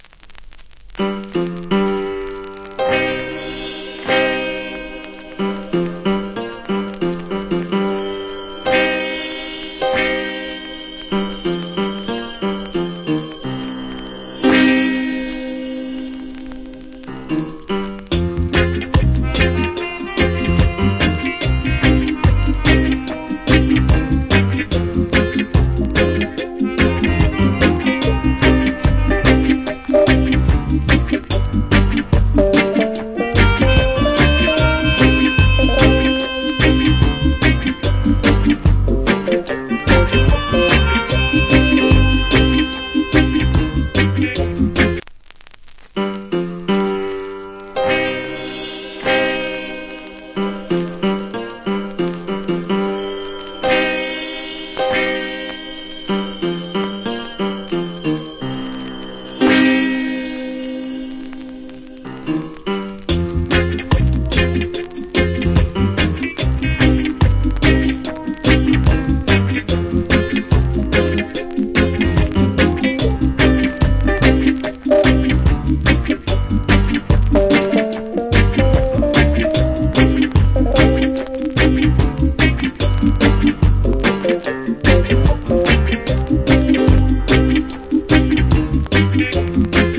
Killer Melodica Inst.